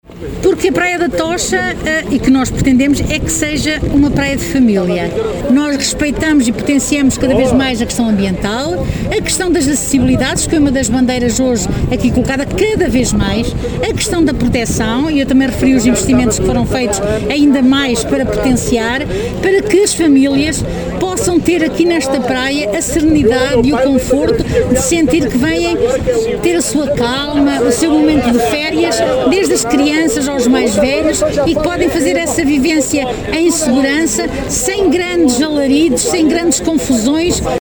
Foi içada hoje, pela 31ª vez, a Bandeira Azul na praia da Tocha, em Cantanhede, numa cerimónia que decorreu no Centro de Interpretação da Arte Xávega.
A presidente da Câmara municipal de Cantanhede aproveitou ainda para realçar a importância desta praia para momentos de lazer das famílias: